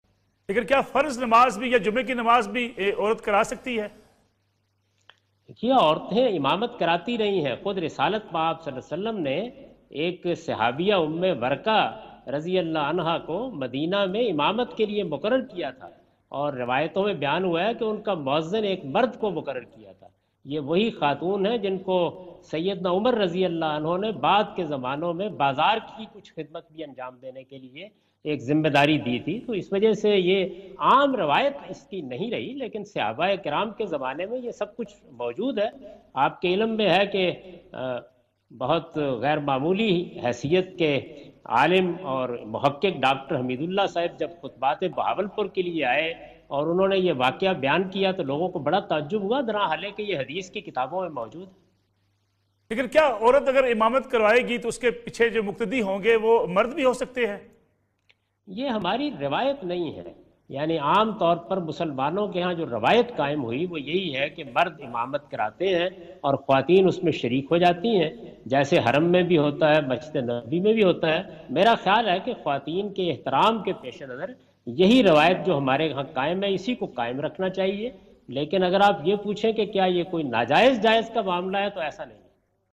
Category: TV Programs / Neo News /
In this program Javed Ahmad Ghamidi answer the question about "Can a Women Lead Prayer" on Neo News.